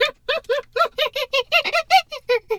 hyena_laugh_02.wav